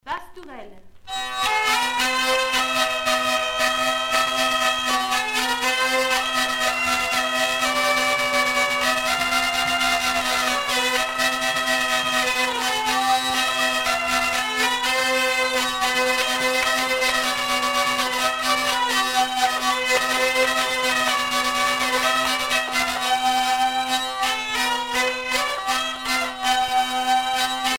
danse : quadrille : pastourelle
Pièce musicale éditée